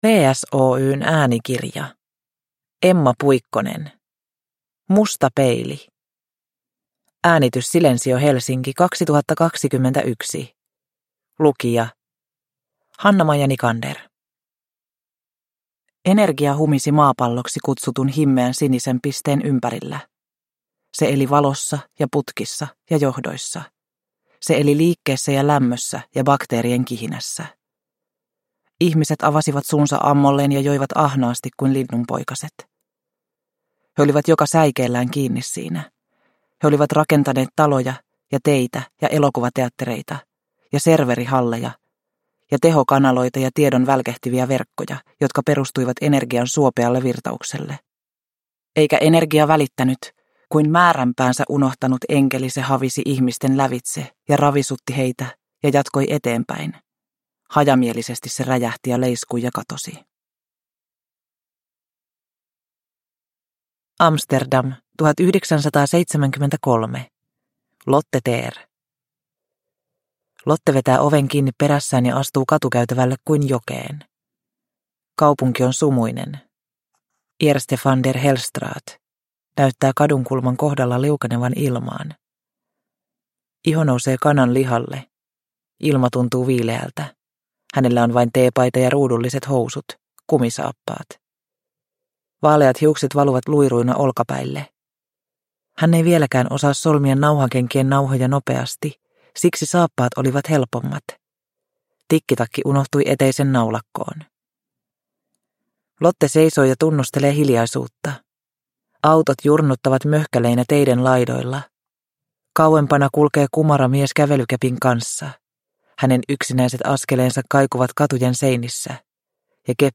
Musta peili – Ljudbok – Laddas ner